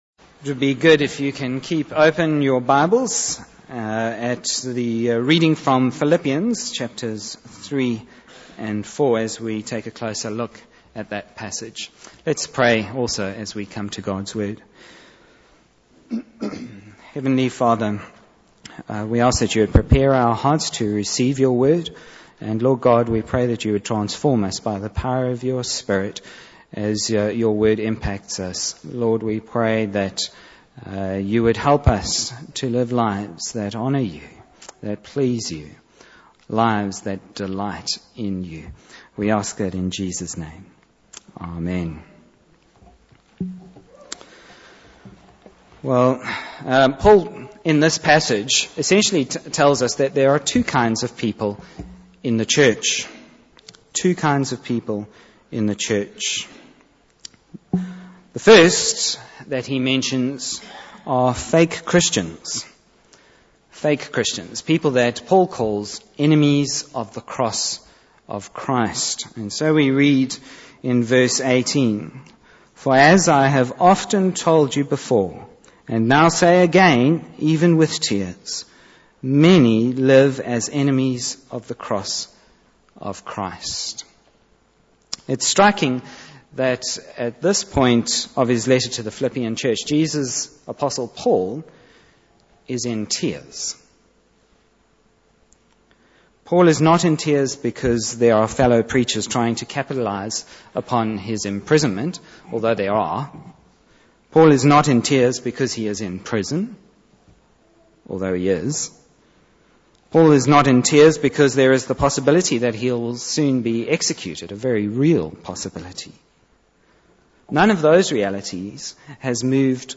Bible Text: Philippians 3:17-4:1 | Preacher: